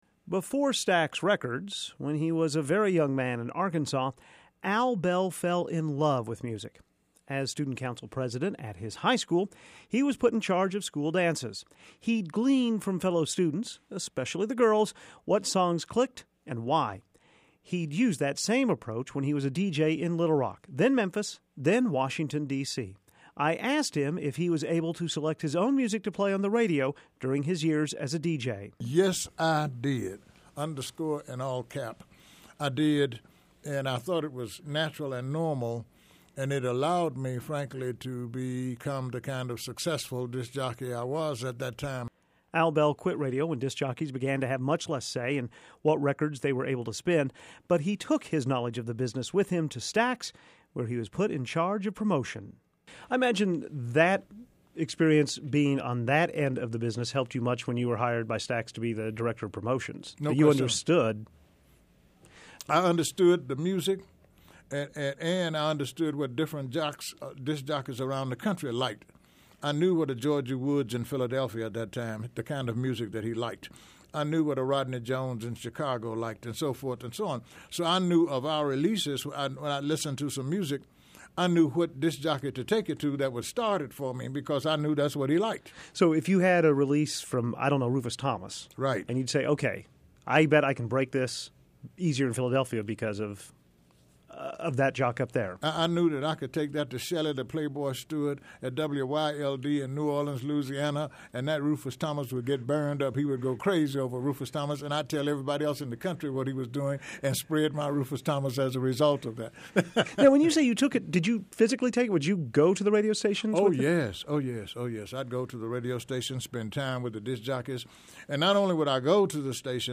Music Legend Visits U of A, KUAF Al_Bell.mp3 Al Bell, the former chairman of Stax Records and former president of Motown Records, visited KUAF last week to talk about his love of music and the inner workings of the music industry. To listen to more of this conversation, click here.